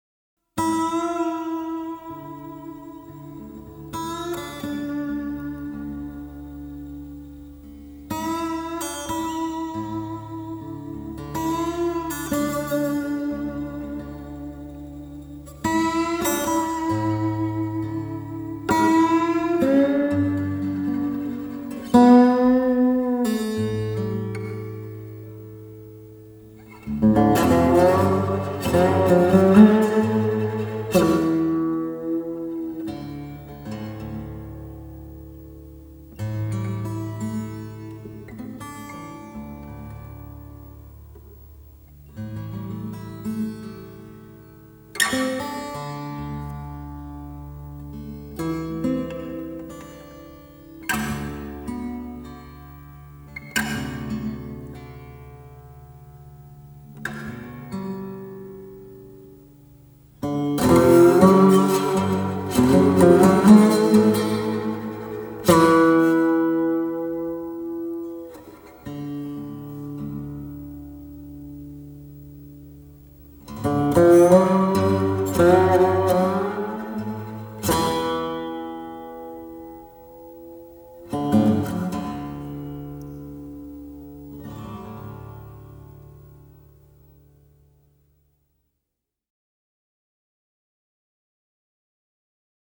每每听到那空灵、孤独的滑棒吉他弦音，听者的思绪会飘到美国西部辽阔而荒芜的沙漠，最后消失在低垂的夜幕中。
音樂類型：電影原聲帶(電影配樂)